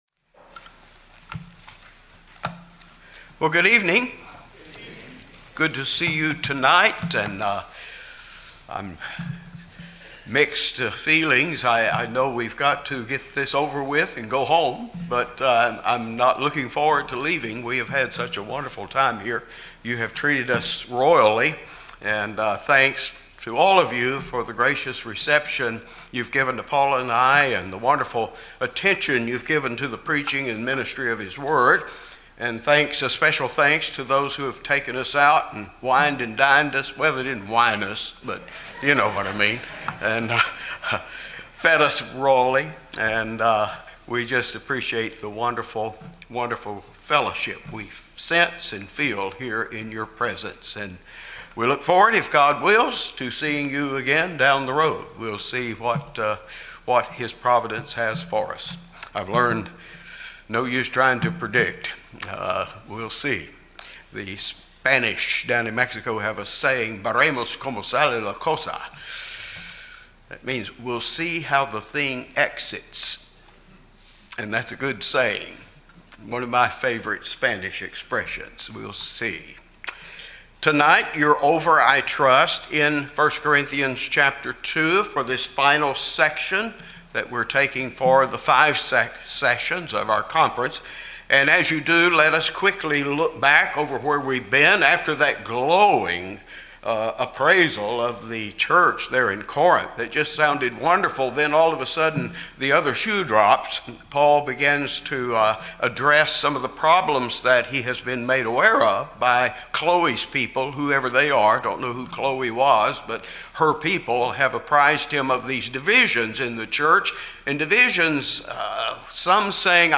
This is the last of five messages in the 2025 Spring Bible Conference.